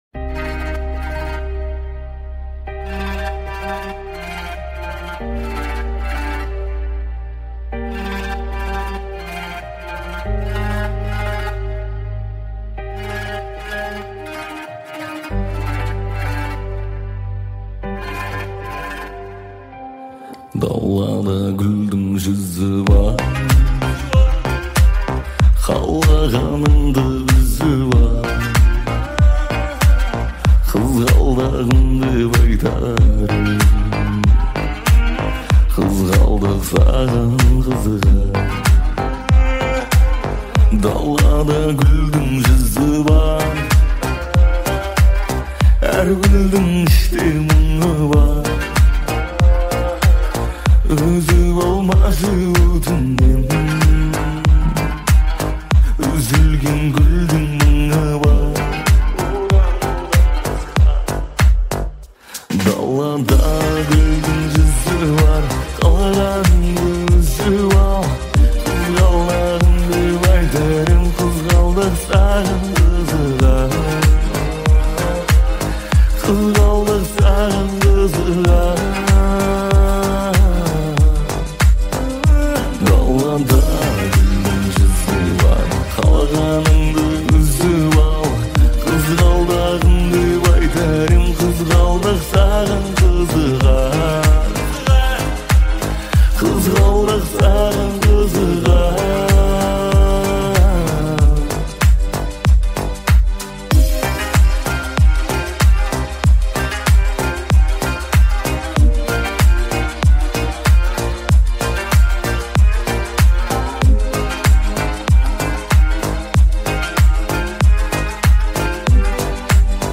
• Жанр: Узбекские песни
(cover)